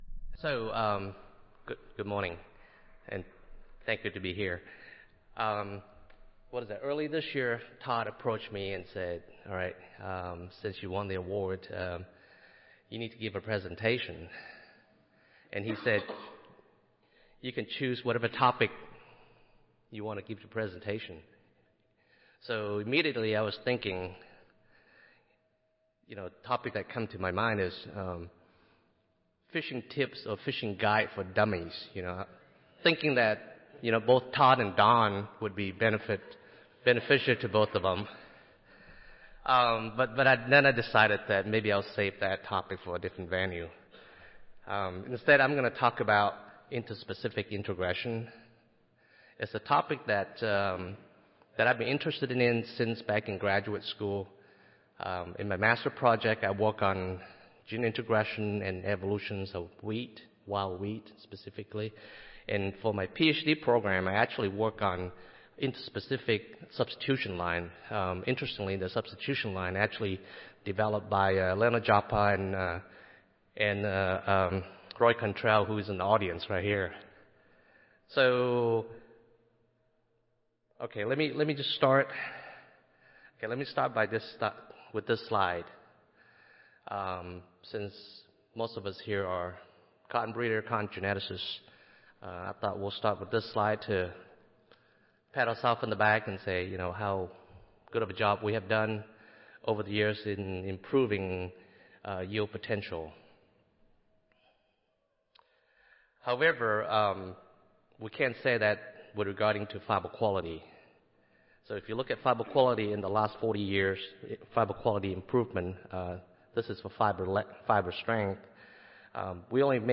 Cotton Genetics Award Lecture - Interspecific Introgression for Cotton Improvement
Recorded Presentation